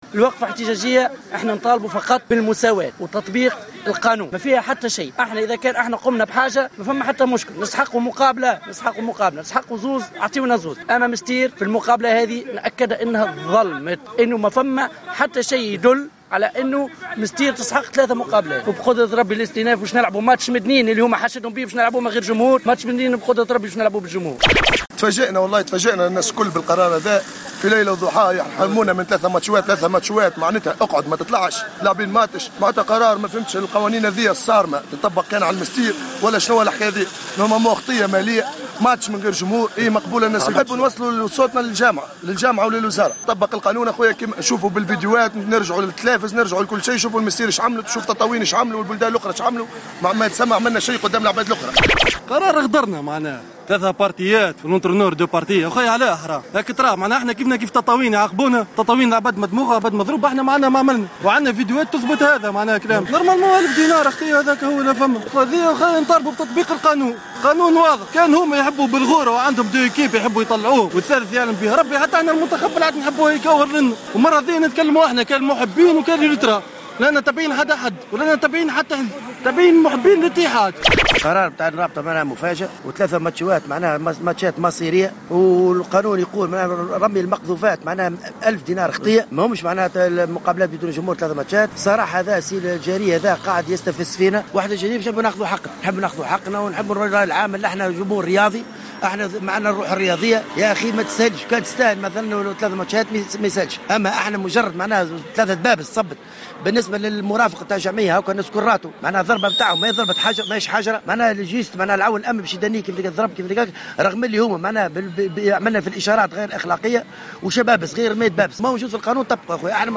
نظمت جماهير الإتحاد المنستيري صباح اليوم وقفة إحتجاجية أمام مقر الولاية تنديدا بقرارات مكتب الرابطة الصادرة يوم أمس و القاضية بتسليط عقوبة اللعب دون حضور الجمهور على الفريق لثلاث مقابلات .